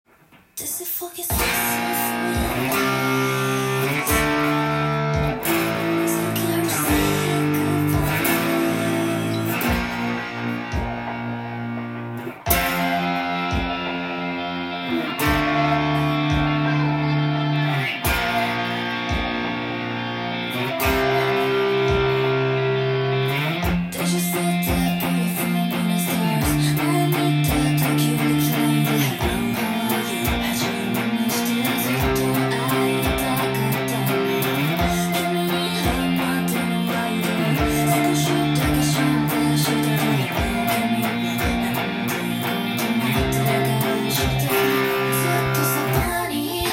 音源に合わせて譜面通り弾いてみました
簡単に弾けるようにカンタンTAB譜をパワーコードを使用して
三段目からブリッジミュートは登場するので